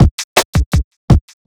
HP082BEAT2-R.wav